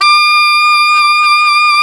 Index of /90_sSampleCDs/Roland L-CDX-03 Disk 1/SAX_Alto Tube/SAX_Alto ff Tube
SAX ALTOFF0N.wav